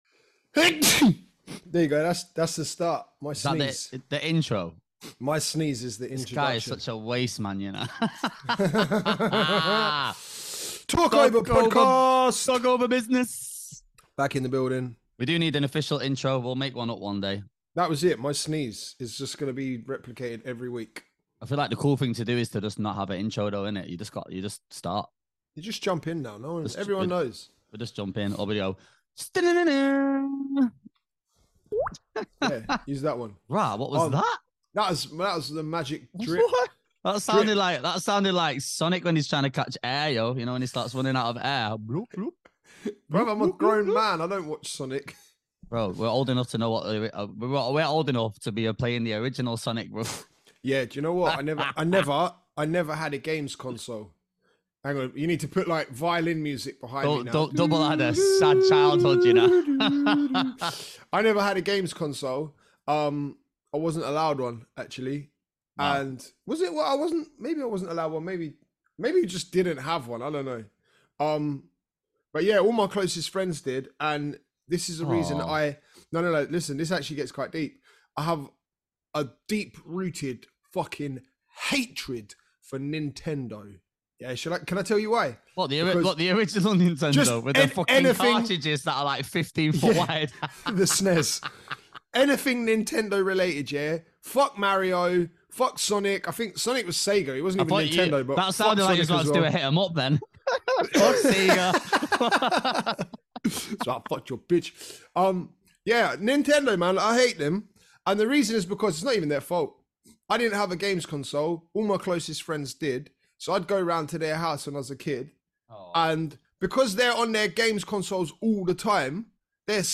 two DJs from the UK & we've decided that there needs to be an outlet for various topics relating to the DJ world and all things surrounding it…